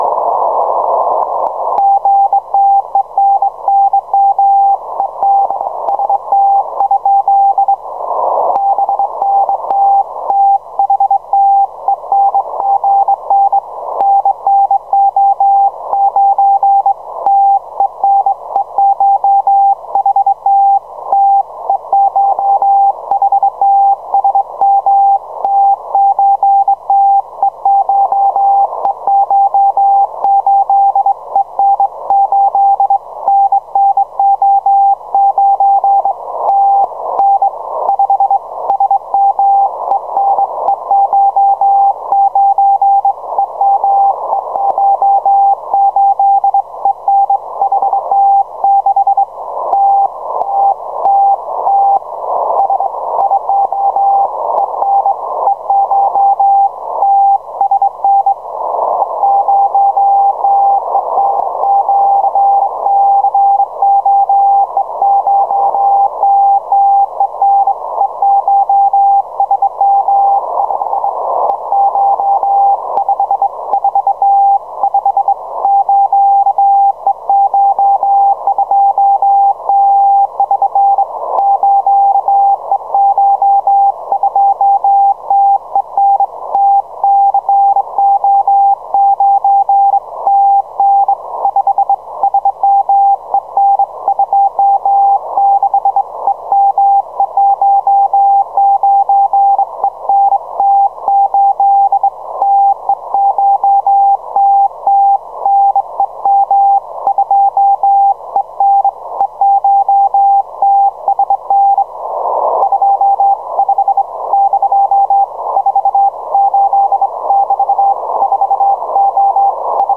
Émissions télégraphiques exotiques
Chalutiers présumés japonnais sur 4191 kHz et commandement de l'aviation à longue portée des forces aériennes russes sur 4179 kHz :